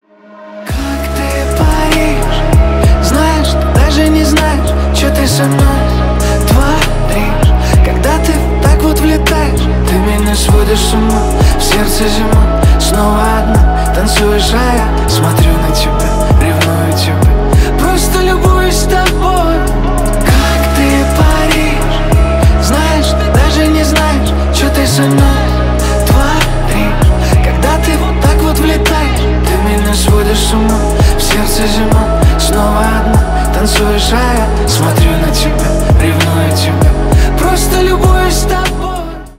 романтические
медленные